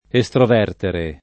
vai all'elenco alfabetico delle voci ingrandisci il carattere 100% rimpicciolisci il carattere stampa invia tramite posta elettronica codividi su Facebook estrovertere [ e S trov $ rtere ] v.; estroverto [ e S trov $ rto ]